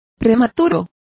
Complete with pronunciation of the translation of premature.